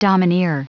Prononciation du mot domineer en anglais (fichier audio)
Prononciation du mot : domineer